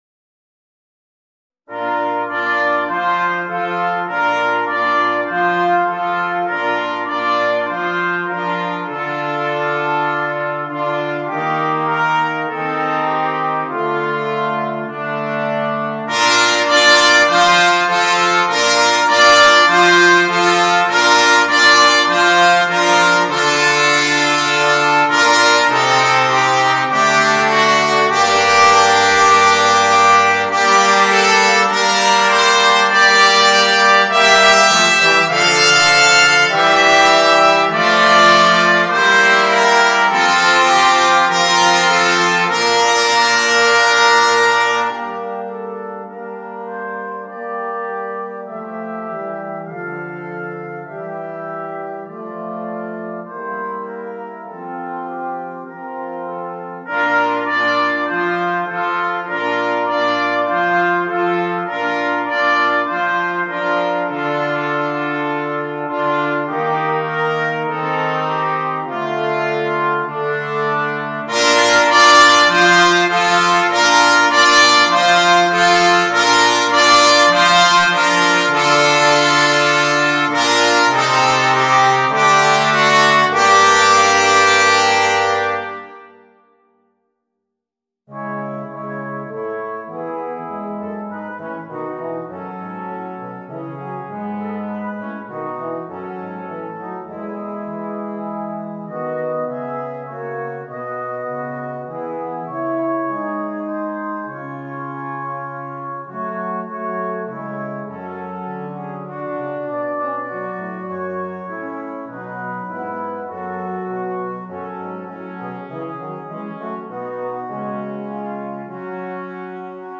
For Brass Quartet